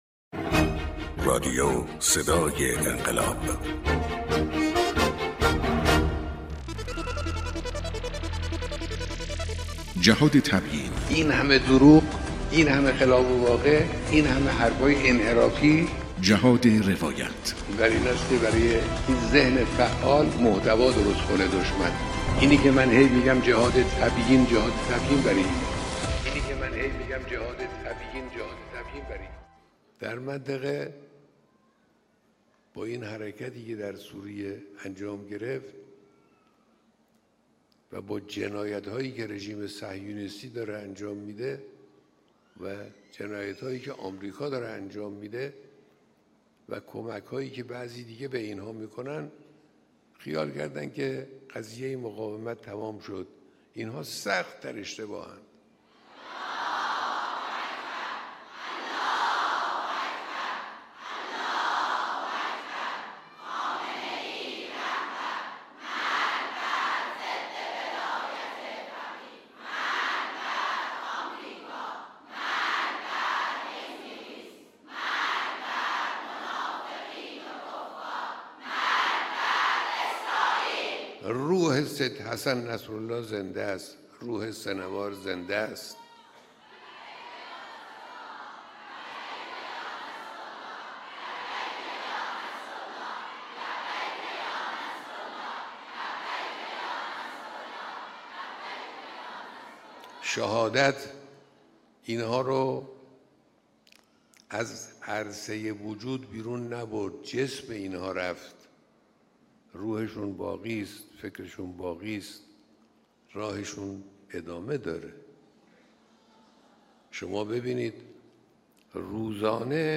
رادیو صدای انقلاب 1554 | جهاد تبیین : سخنان روشنگرانه‌ی مقام معظم رهبری